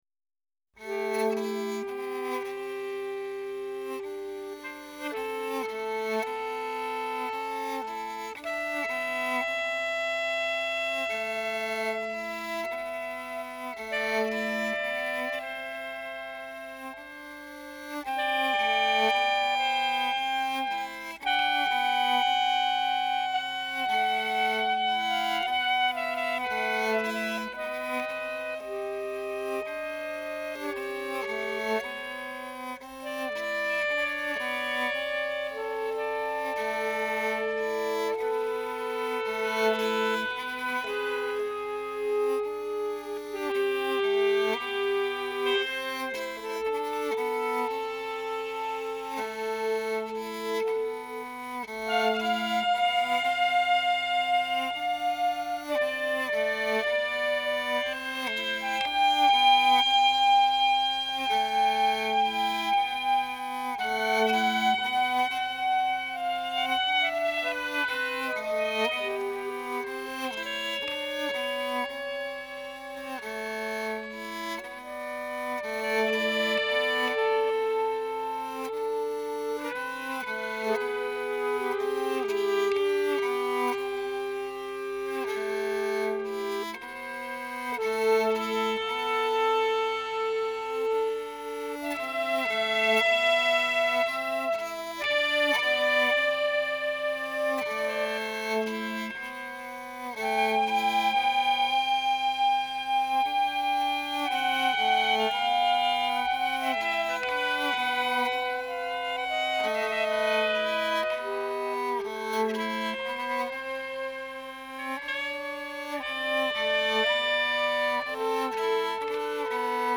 soprano & alto sax
kokyu & voice